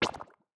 SFX_Slime_Hit_V2_01.wav